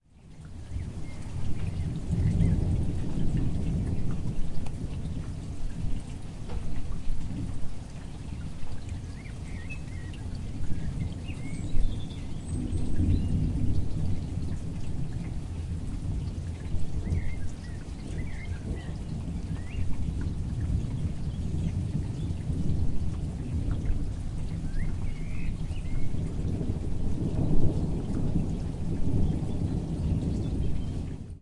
描述：......紧闭的屋顶窗户上的雨水......
Tag: 氛围 自然 souinscape 声音 风暴 雷电